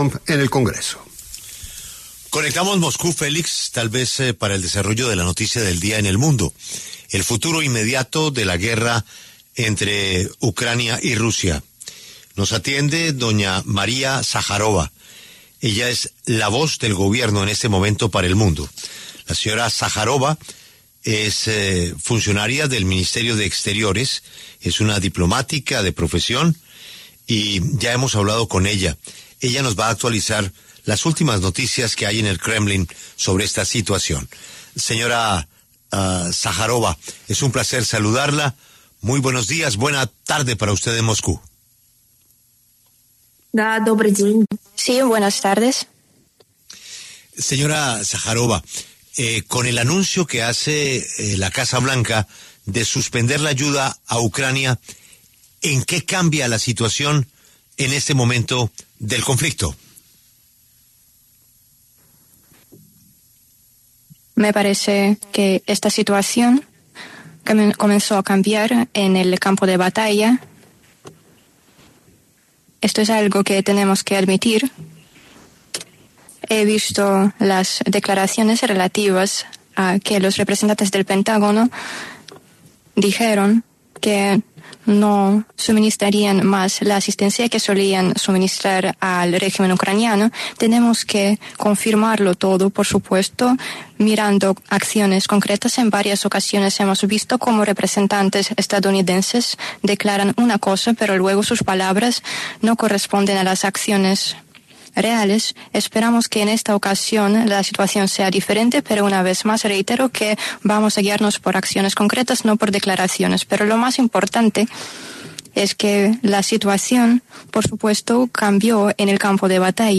En diálogo con La W, María Zajárova, portavoz del Ministerio de Asuntos Exteriores de Rusia, se refirió a la guerra con Ucrania.